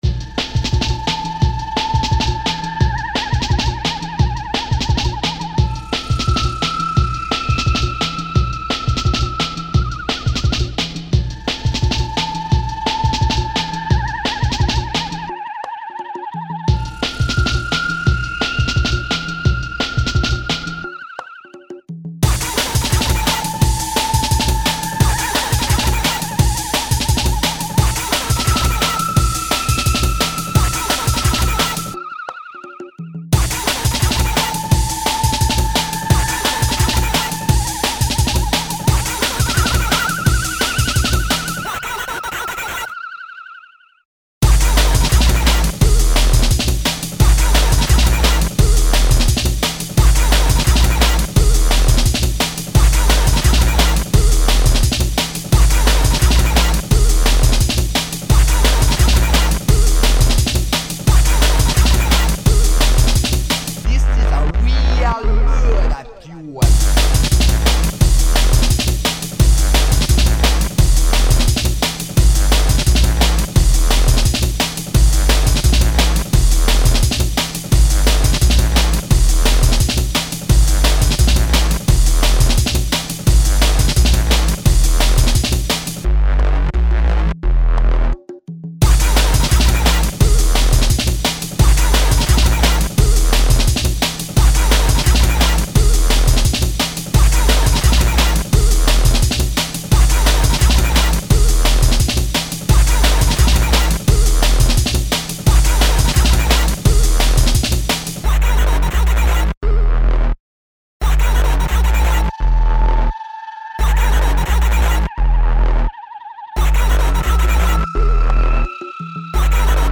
a hangzás viszonylag kiforrott, de kicsit egyszerű még. az a vokóderezett beszéd engem személy szerint idegesít, azt kicserélném valami másra
sztem csak torzított, nem vokóderezett, de tény hogy kicsit túl sok belőle.
meg más féle bassline válthatná ezt a mostanit néha, így kicsit egyhangú, valami jó kis wobble jól passzolna ehhez a koszos breakhez